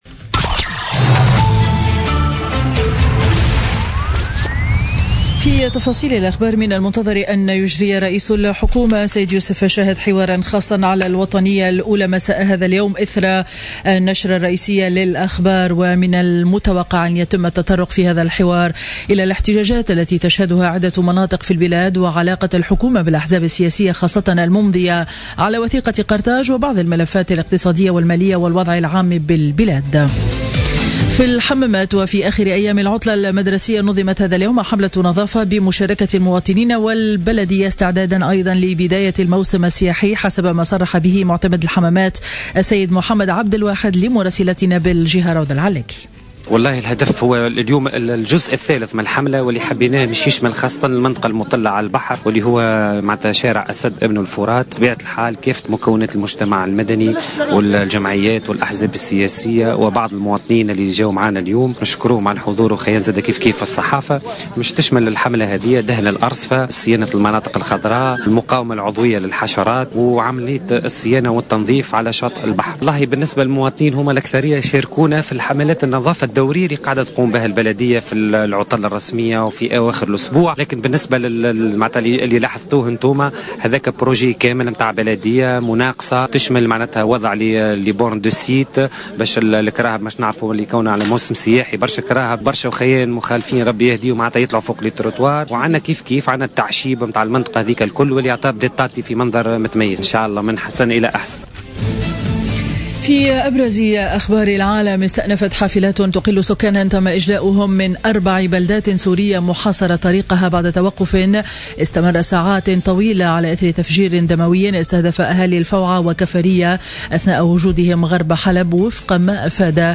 نشرة أخبار منتصف النهار ليوم الأحد 16 أفريل 2017